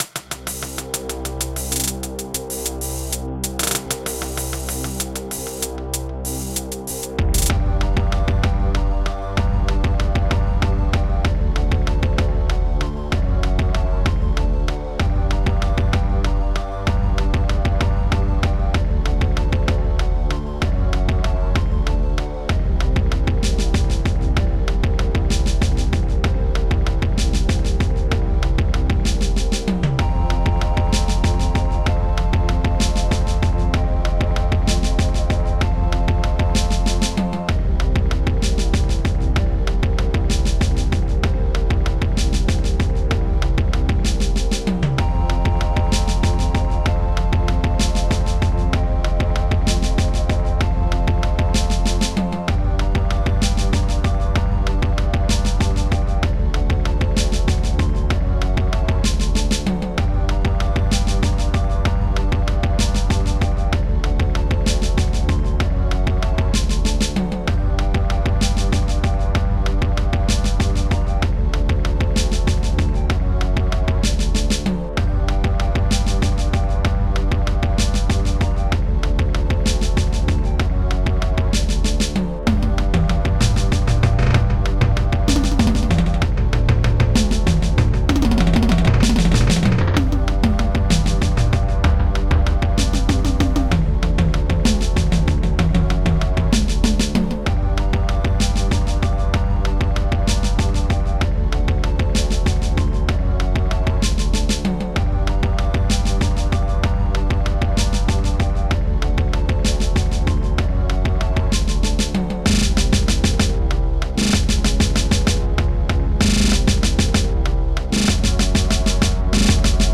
Protracker and family
sinus-bass
closed-hihat_2
open-hihat_2